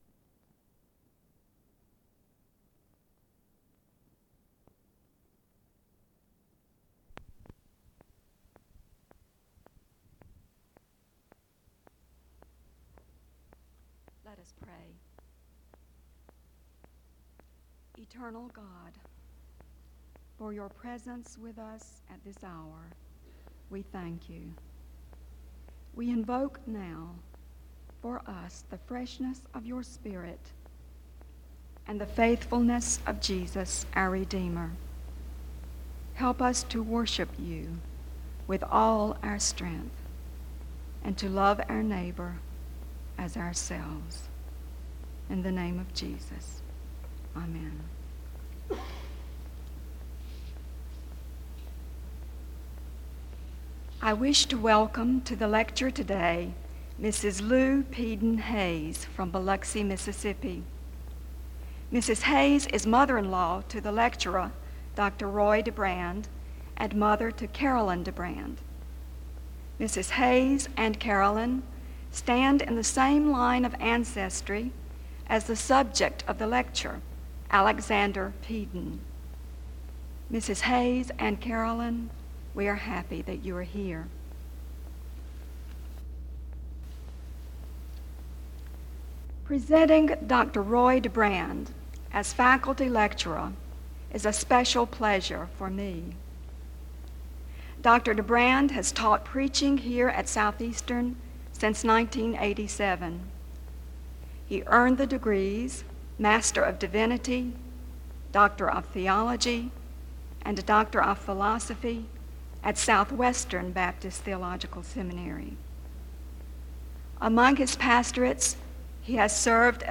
The audio was transferred from audio cassette.
There is a prayer and introduction from 0:12-3:45.
Download .mp3 Description The audio was transferred from audio cassette.
There are closing remarks from 41:51-42:24.